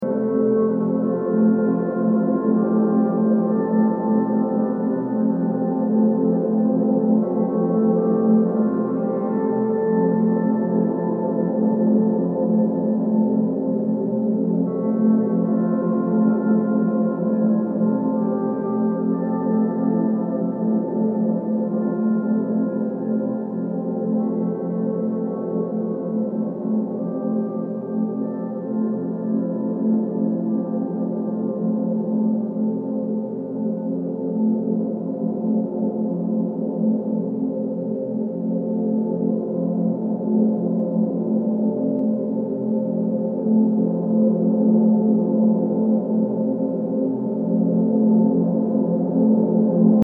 Ambient, Drone >